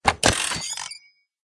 telephone_hang_up.ogg